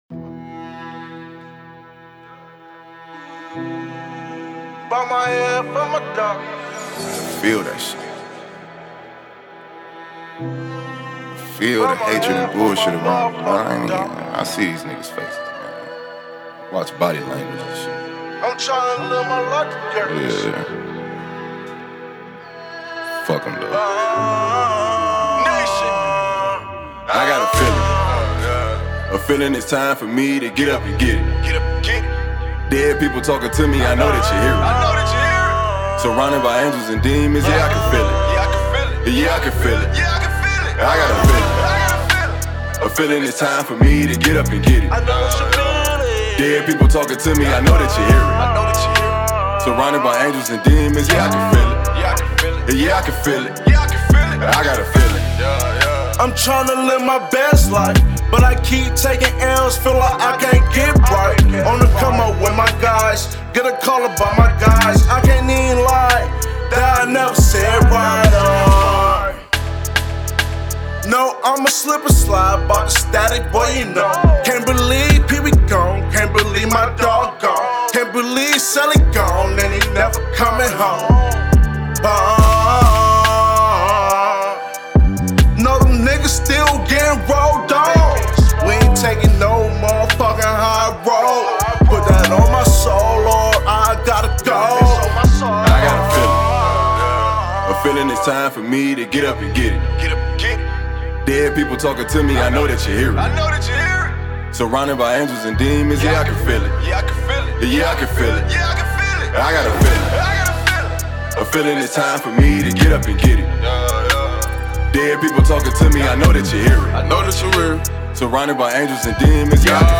Hiphop
soulful and pain-filled inspirational track